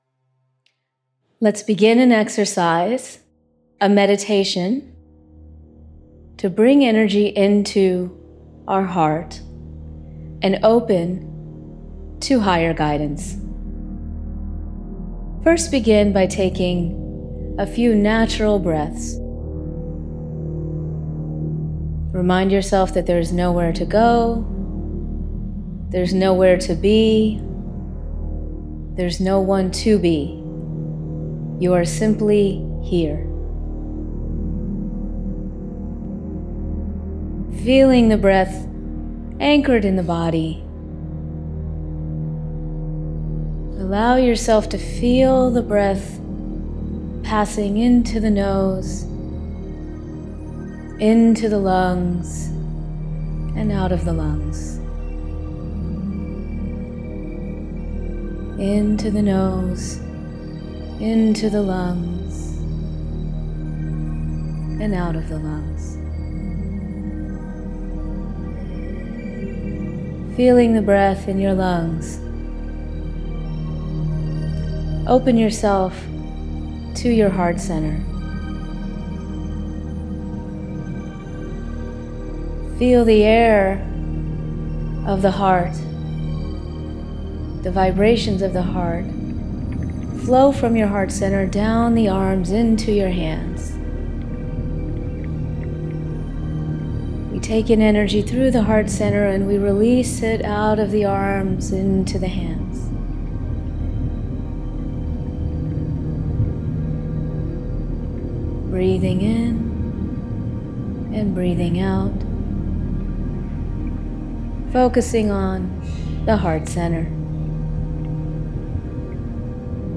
Download the Meditation and Healing Report
Heart_s Guidance Meditation.mp3